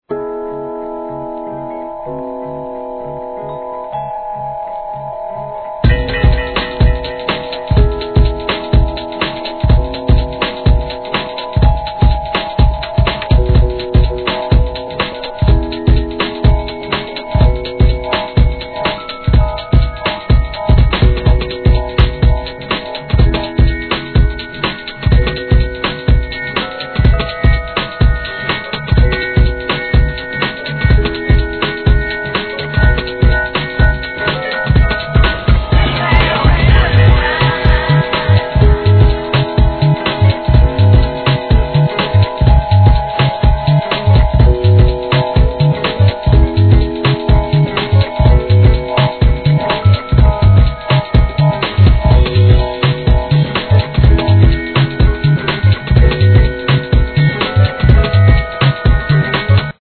HIP HOP/R&B
ボッサやブロークンビーツ調、ブラジリアンハウスとあくまで生音メインでお洒落に高揚感を演出!!!